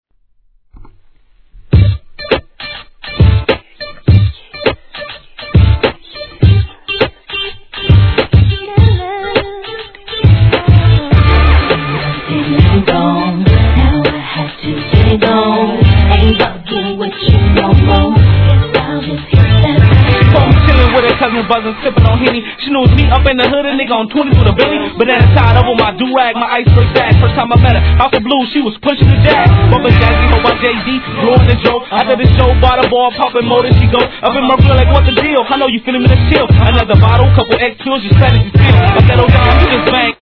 HIP HOP/R&B
ダイナミックなサウンドに女性コーラスが絡むキャッチーな一曲!!